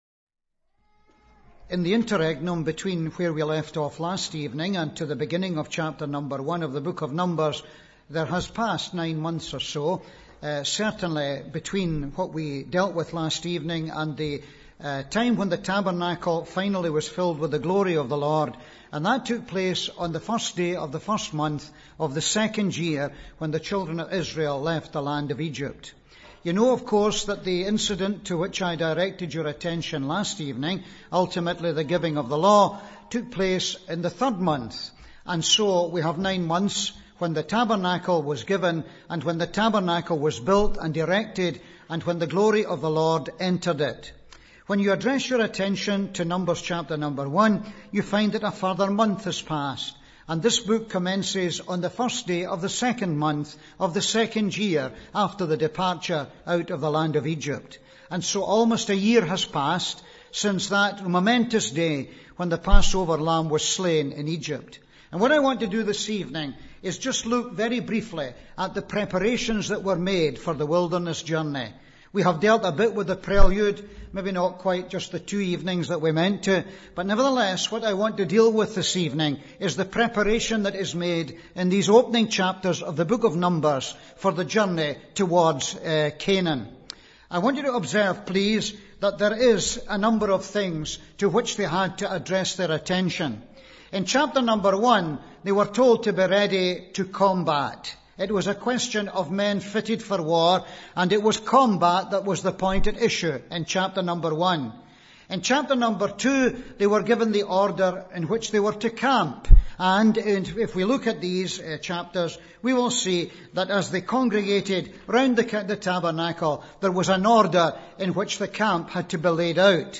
Readings: Num 1:1-4, 17-18, 2:1-3a, 3:1-6, 5:11-15, 28, 8:1-4 (Message preached in Stark Road Gospel Hall, Detroit, 2007).